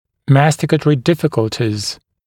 [‘mæstɪkətərɪ ‘dɪfɪkəltɪz][‘мэстикэтэри ‘дификэлтиз]нарушения жевательной функции, проблемы при жевании, затруднения при жевании